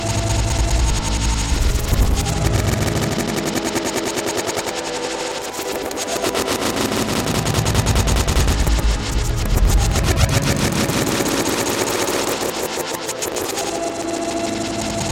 beach fractured texture boards.ogg
Original creative-commons licensed sounds for DJ's and music producers, recorded with high quality studio microphones.